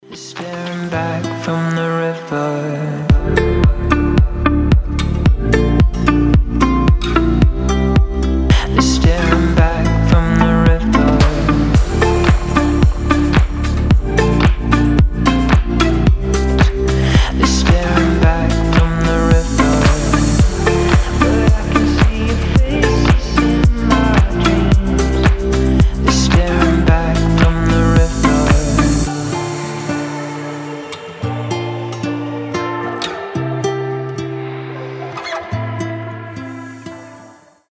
• Качество: 320, Stereo
deep house
красивая мелодия
deep progressive
Красивое звучание с какими-то интересными примочками